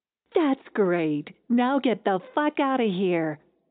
*⃣ Asterisk sound 'get_bleep_outta.wav'
🇺🇸 Spoken in US English